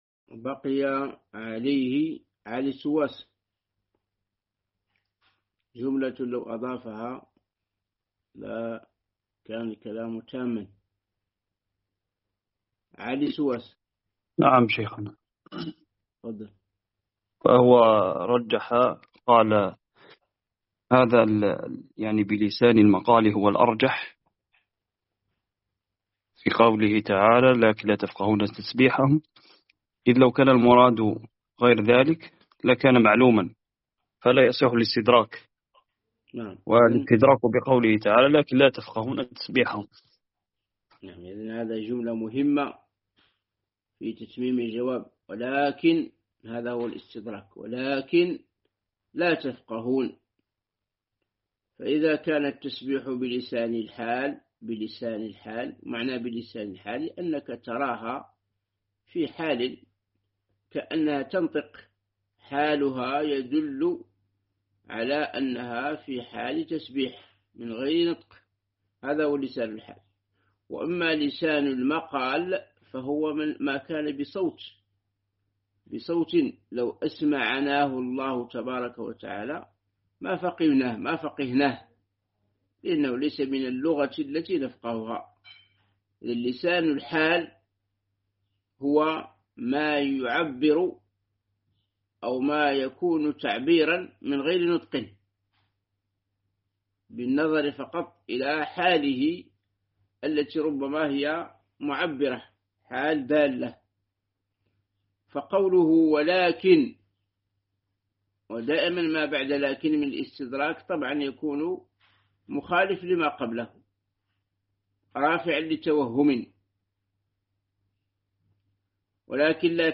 شرح العقيدة الواسطية الدرس 27